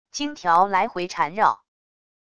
茎条来回缠绕wav音频